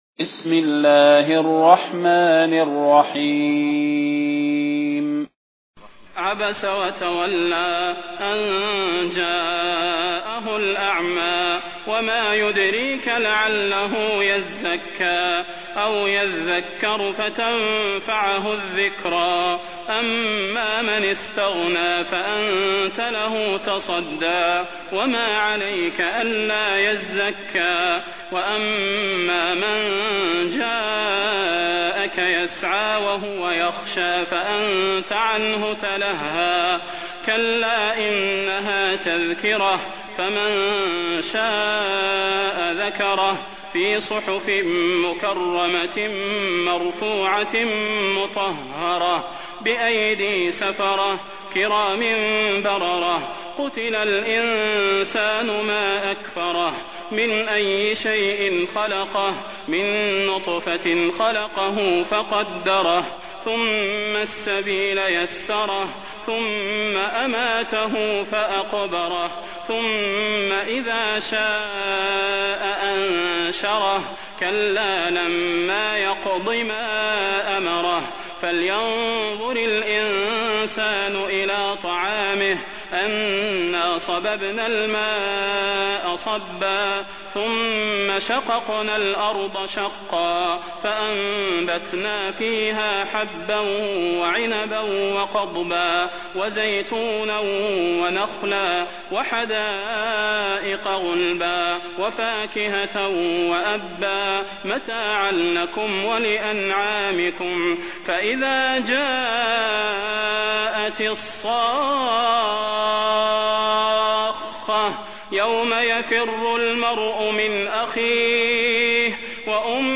المكان: المسجد النبوي الشيخ: فضيلة الشيخ د. صلاح بن محمد البدير فضيلة الشيخ د. صلاح بن محمد البدير عبس